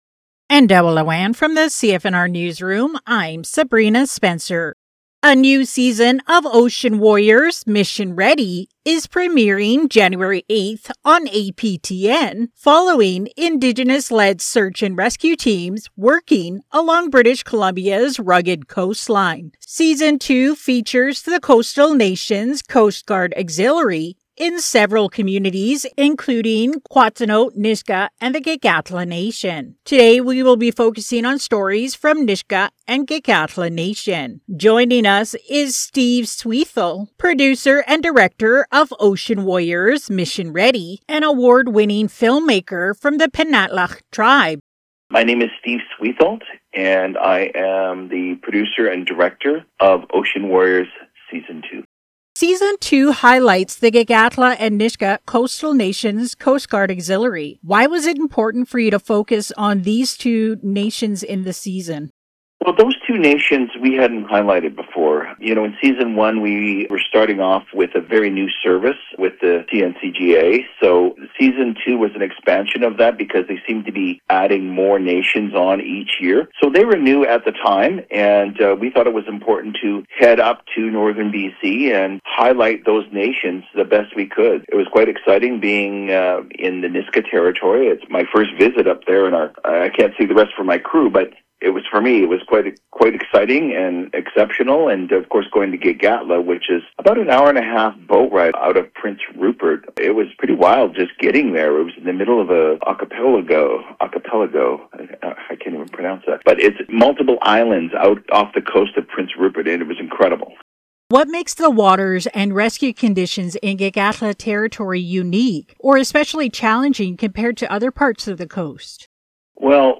In an interview with CFNR News